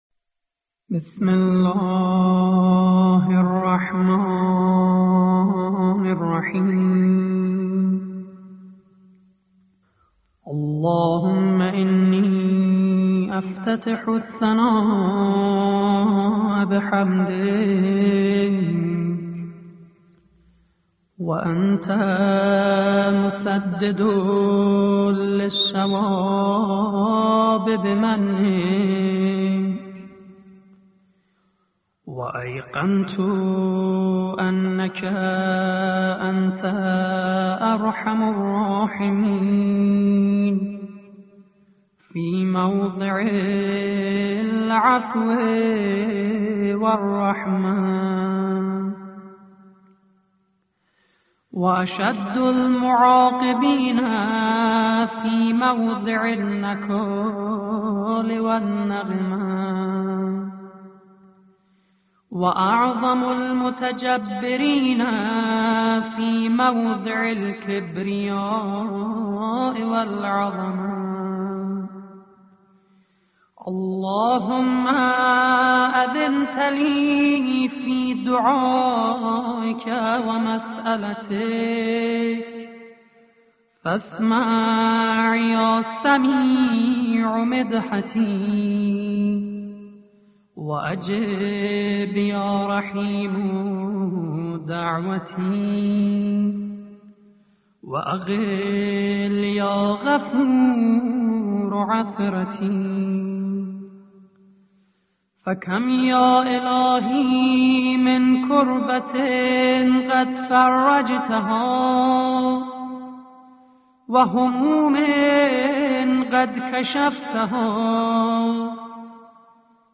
قرائت دعای افتتاح